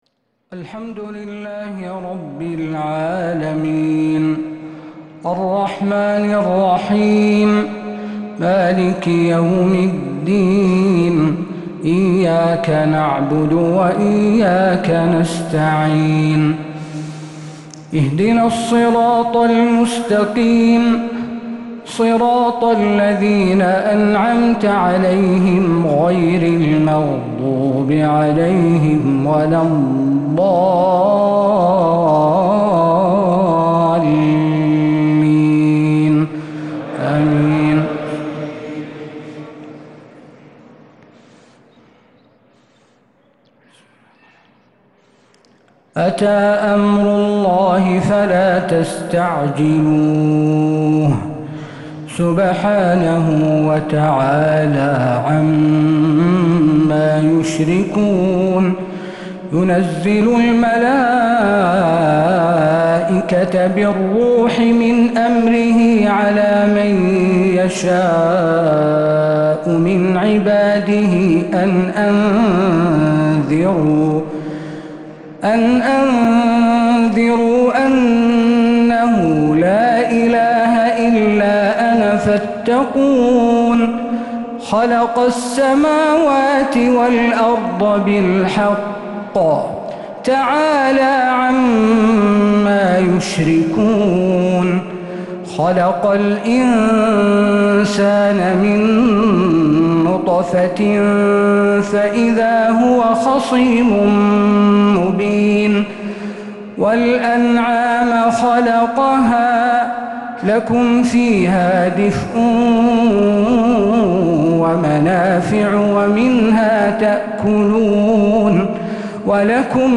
تراويح ليلة 18 رمضان 1446هـ فواتح سورة النحل (1-55) | Taraweeh 18th night Ramadan 1446H Surat An-Nahl > تراويح الحرم النبوي عام 1446 🕌 > التراويح - تلاوات الحرمين